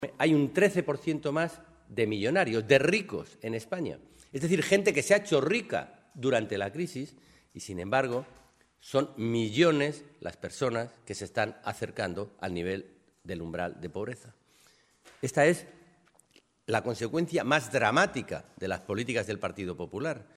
En una comparecencia en Toledo ante los medios de comunicación, Alonso ha resumido la mitad de la legislatura del PP diciendo que “dos años después tenemos más paro, más deuda y más pobreza”.
Cortes de audio de la rueda de prensa